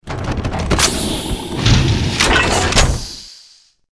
plasmacan.wav